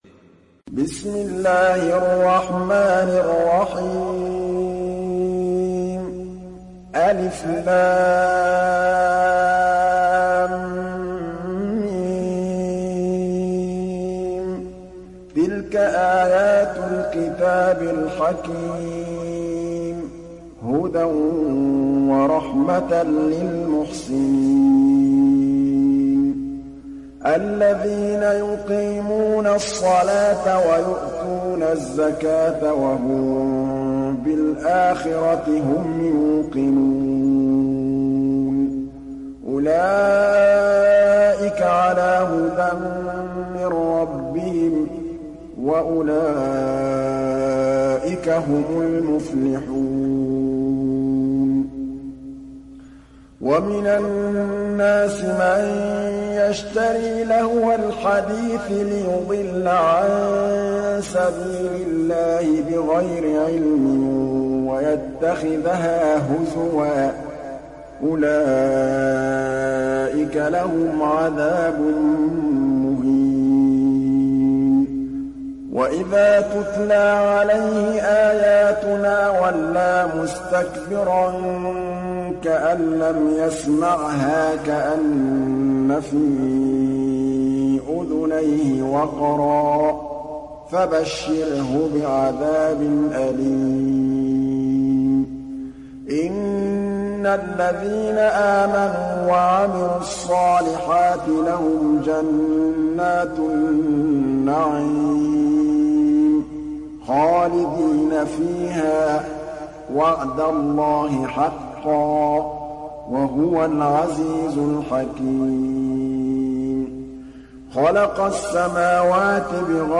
Surat Luqman mp3 Download Muhammad Mahmood Al Tablawi (Riwayat Hafs)